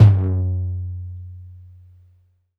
Index of /4 DRUM N BASS:JUNGLE BEATS/KIT SAMPLES/DRUM N BASS KIT 1
FLOOR TOM LONG 2.wav